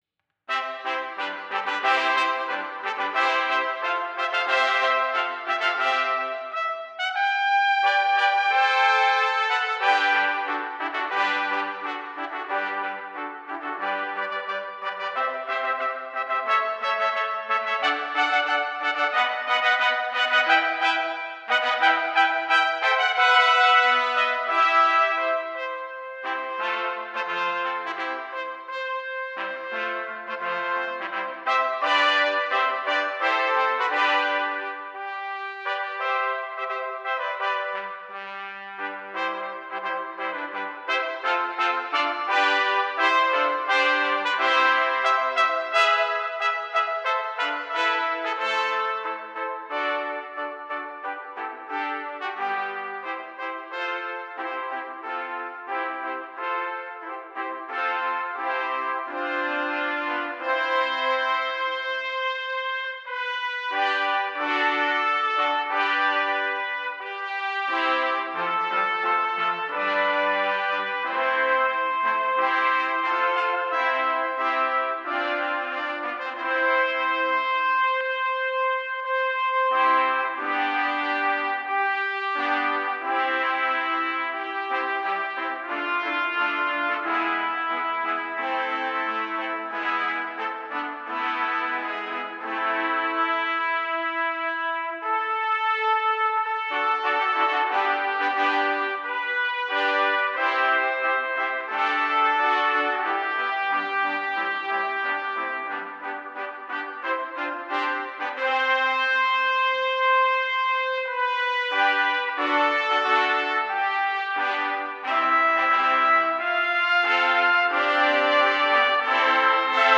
All recordings are overdubs
for 12 trps